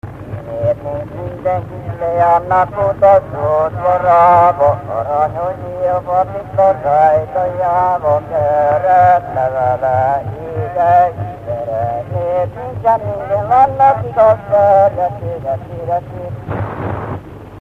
Felföld - Esztergom vm. - Esztergom
Előadó: férfi, ének
Stílus: 8. Újszerű kisambitusú dallamok
Kadencia: 3 (3) 2 1